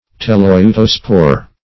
Search Result for " teleutospore" : The Collaborative International Dictionary of English v.0.48: Teleutospore \Te*leu"to*spore\, n. [Gr.
teleutospore.mp3